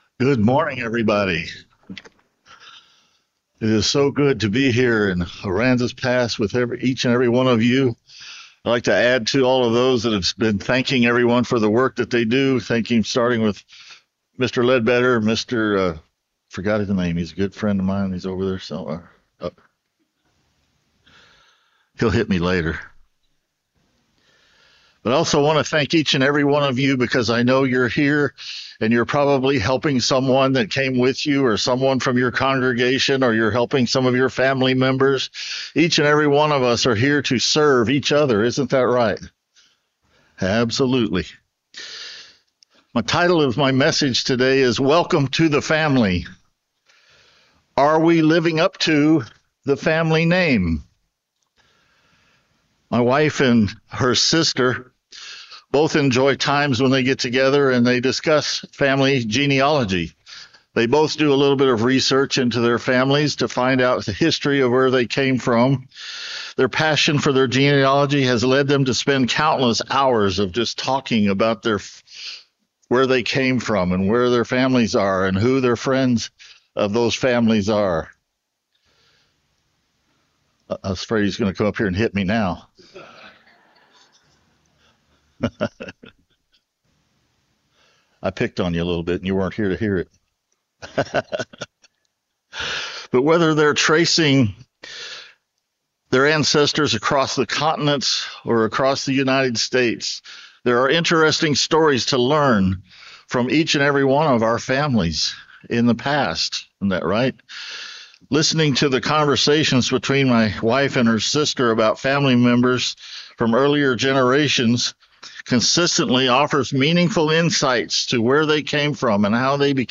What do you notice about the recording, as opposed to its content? Given in Aransas Pass, Texas